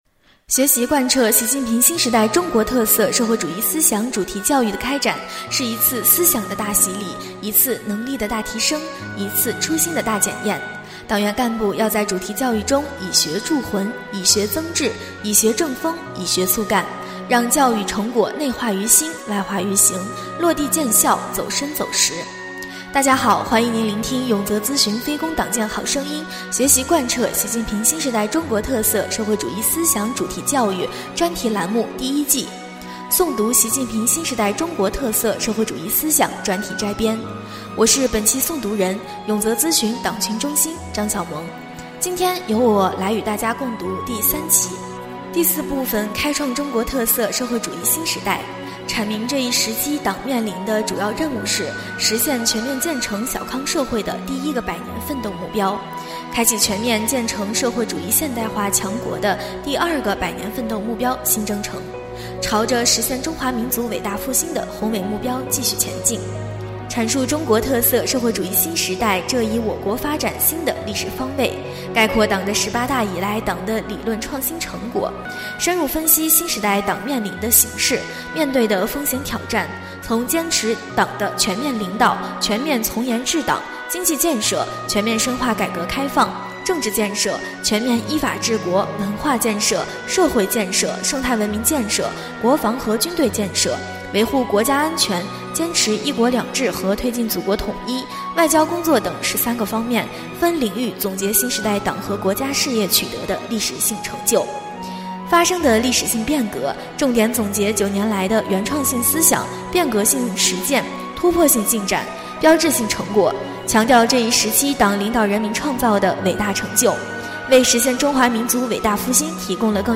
【诵读】《习近平新时代中国特色社会主义思想专题摘编》第3期-永泽党建